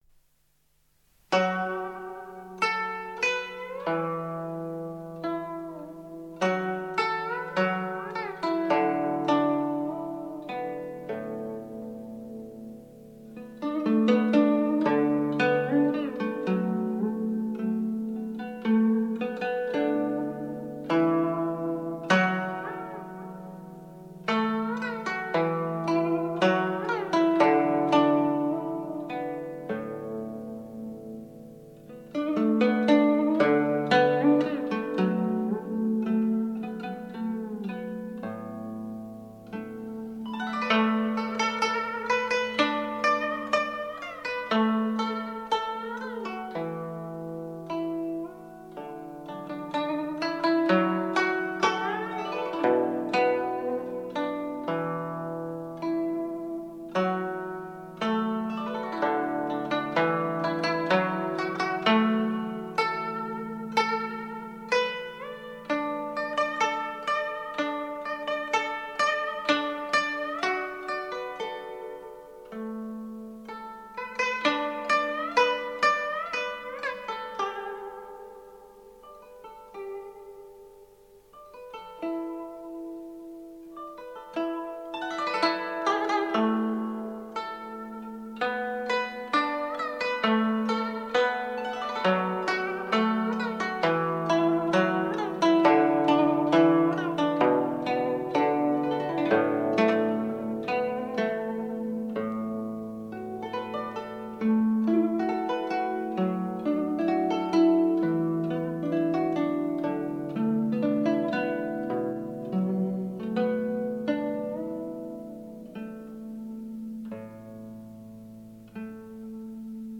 古筝演奏